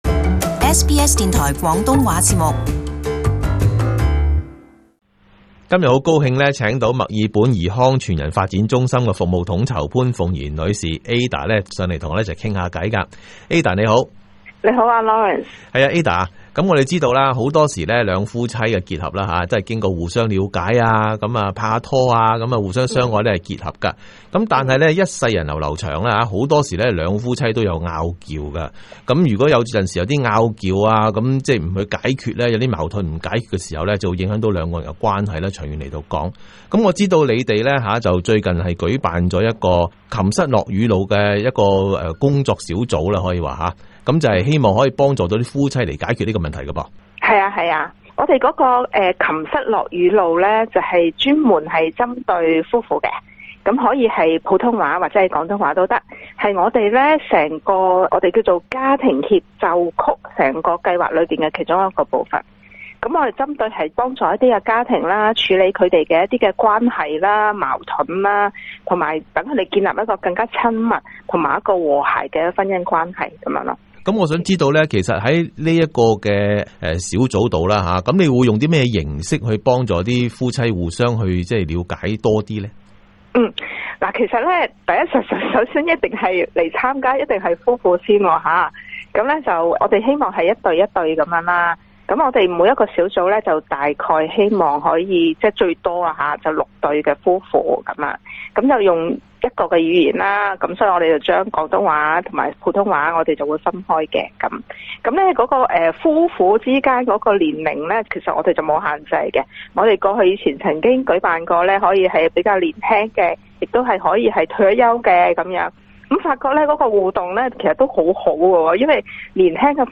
【社區專訪】墨爾本怡康全人發展中心之“琴瑟樂與怒”